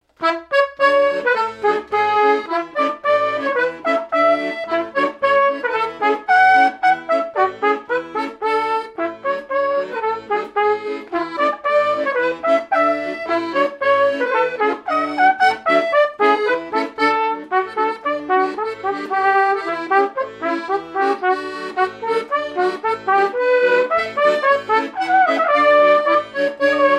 danse : polka
airs de danses issus de groupes folkloriques locaux
Pièce musicale inédite